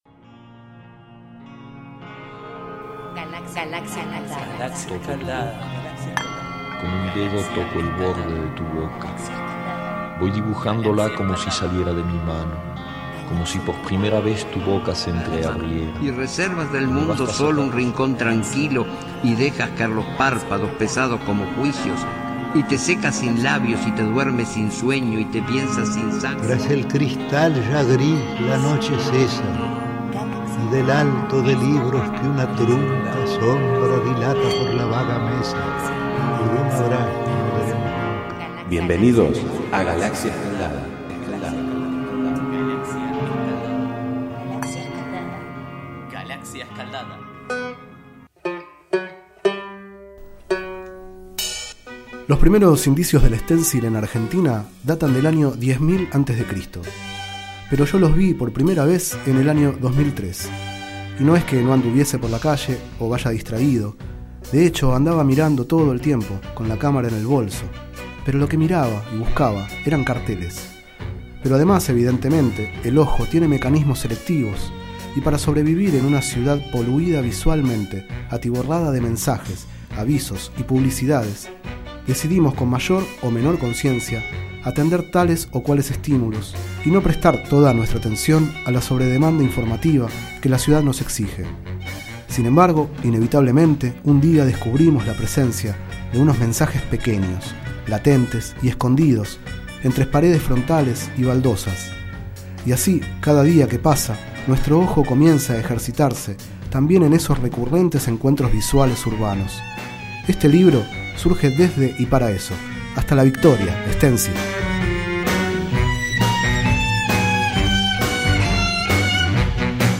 Este es el 01º micro radial, emitido en los programas Enredados, de la Red de Cultura de Boedo, y En Ayunas, el mañanero de Boedo, por FMBoedo, realizado el 23 de marzo de 2013, sobre el libro Hasta la victoria, Sténcil, de Guido Indij (ed.).